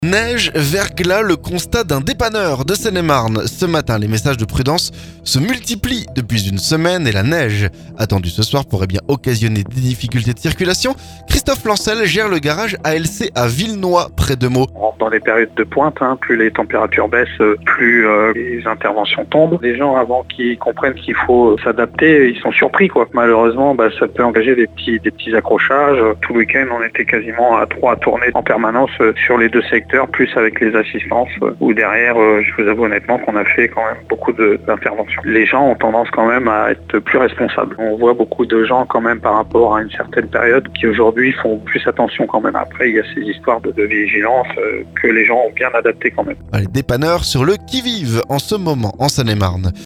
NEIGE/VERGLAS - Le témoignage d'un dépanneur de Seine-et-Marne